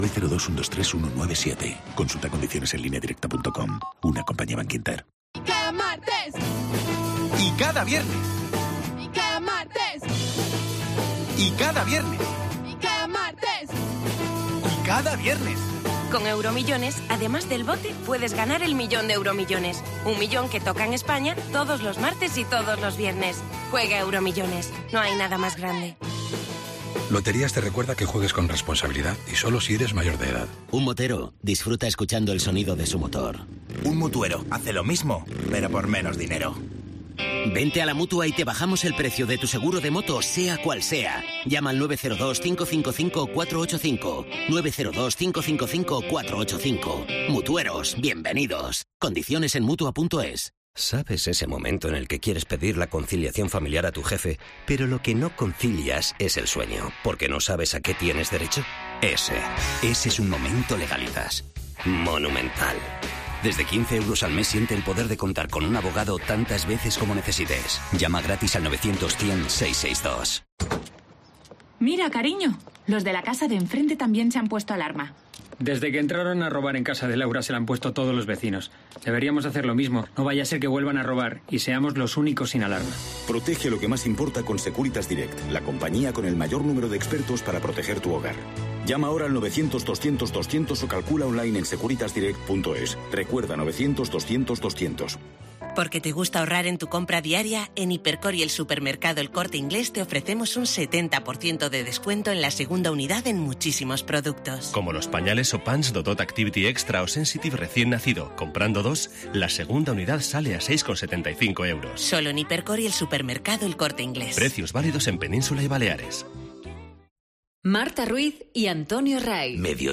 Informativo Mediodía 5 marzo 14:20h